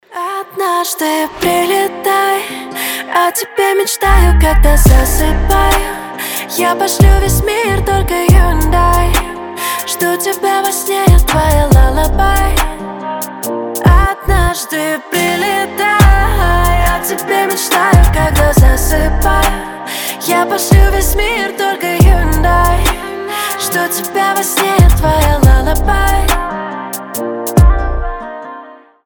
романтические , лирика , колыбельные